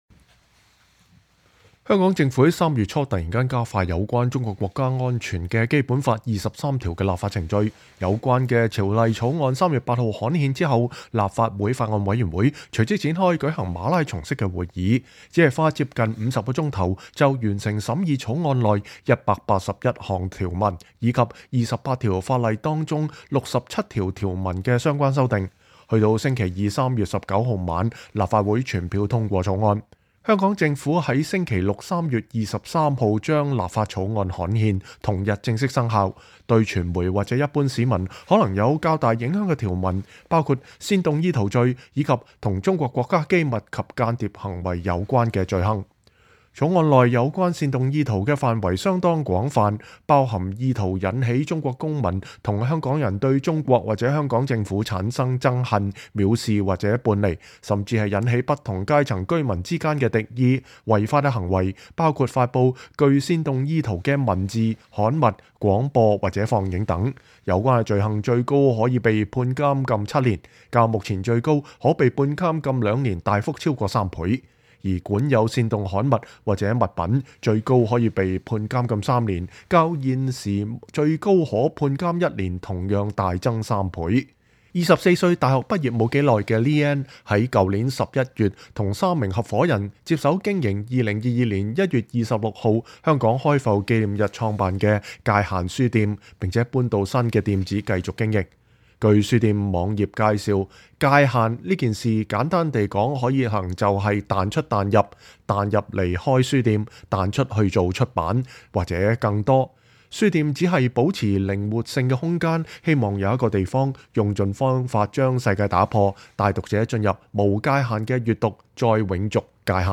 有年輕的獨立書店及出版社經營者，以及作家接受美國之音訪問形容，23條實施後的紅線有如交通規則，每日都有很多人違反交通規則，但不是每個衝紅燈的人都會被檢控，認為執法的力度難以觸摸，會小心應對，亦希望以四格漫畫的創新方式，深入淺出描繪香港歷史的發展吸引年輕讀者。